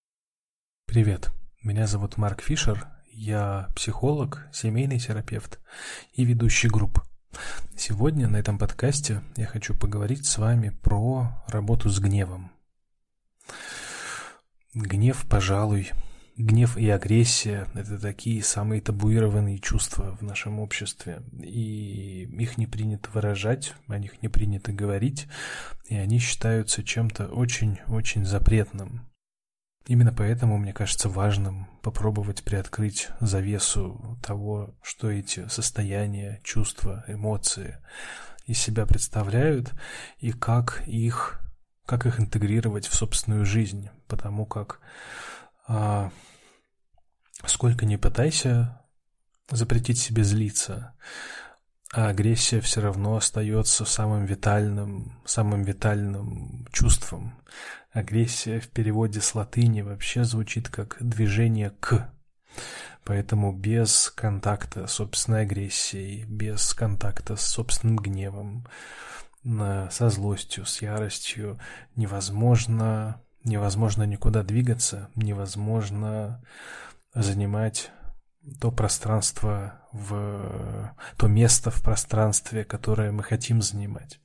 Аудиокнига Аптечка психологической самопомощи: Чувство гнева | Библиотека аудиокниг